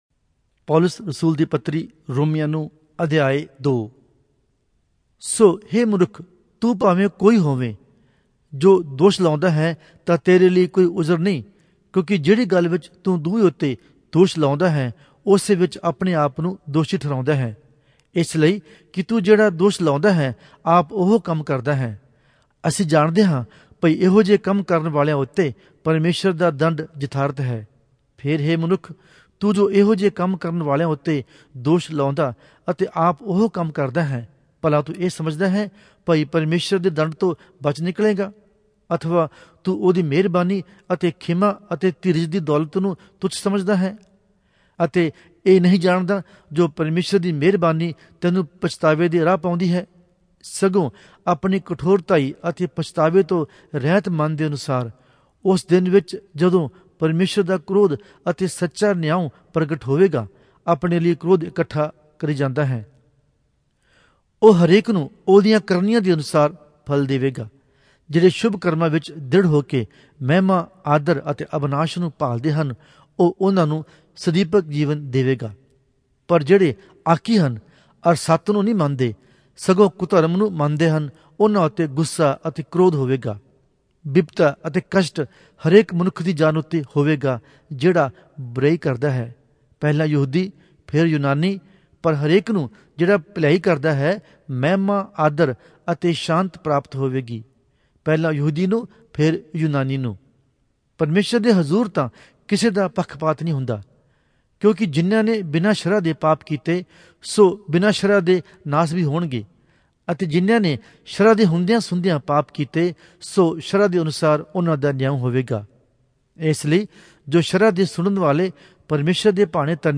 Punjabi Audio Bible - Romans 8 in Mhb bible version